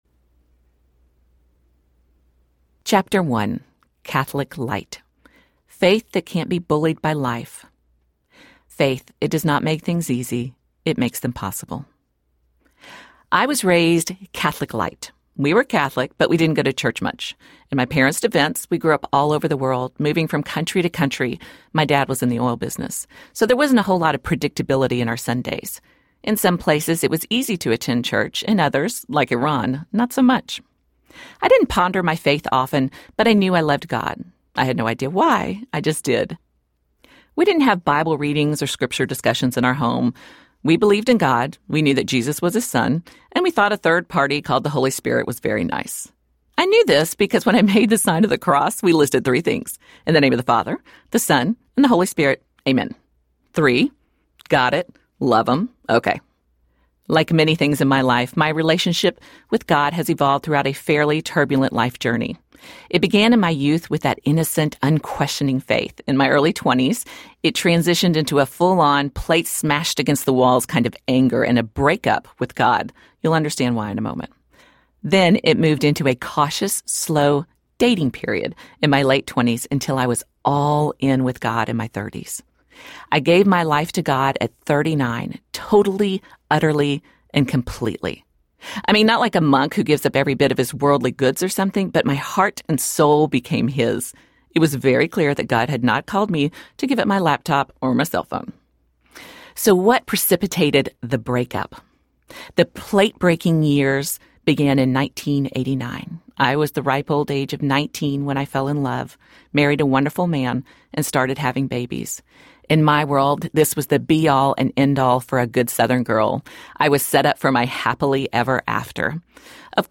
Laughing Through the Ugly Cry Audiobook